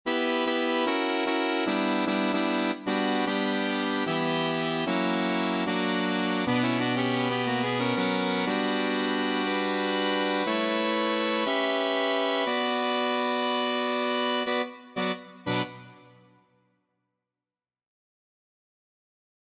Key written in: B Minor
How many parts: 4
Type: Barbershop
All Parts mix: